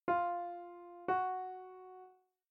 Minor second
Increasing a pitch by one fifteenth (a ratio of 16:15) creates an interval which we call a minor second or half step.
minor2.mp3